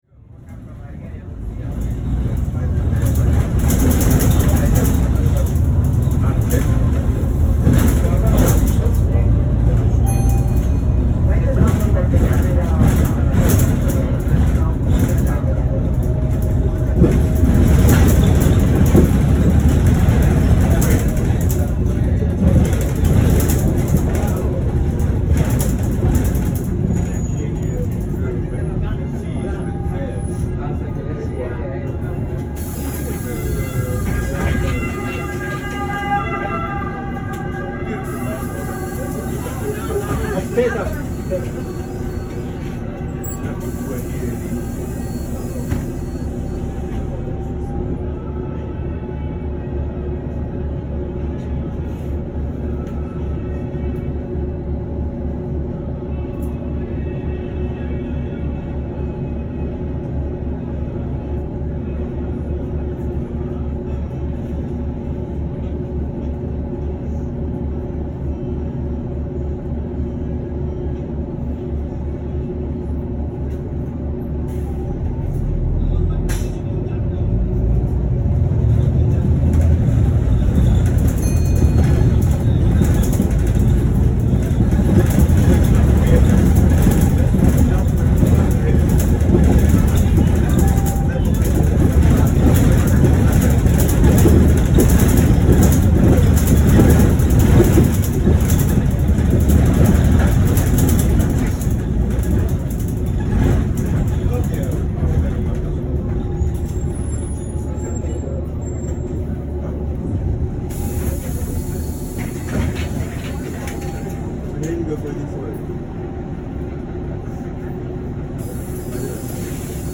Звук автобуса скачать
Общественный транспорт Италия. Городской маршрутный автобус
marshrutka-italy.mp3